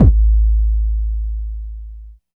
85 KICK 3.wav